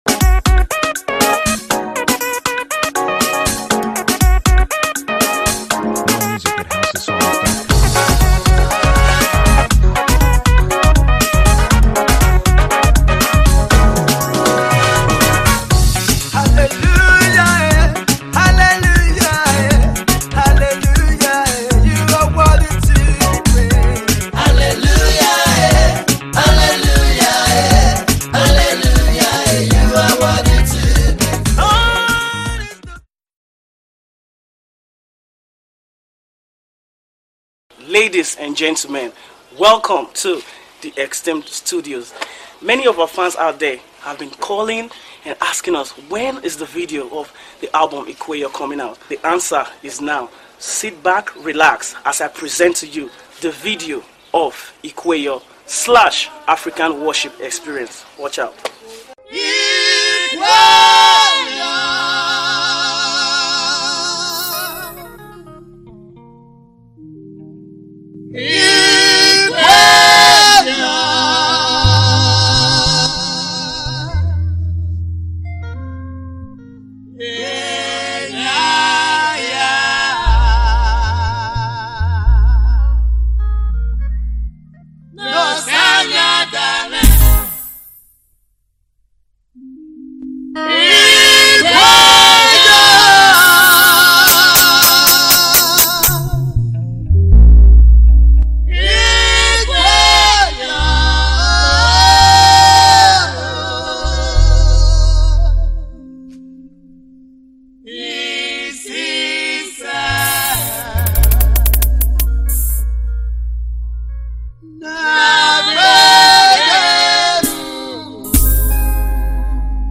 • Gospel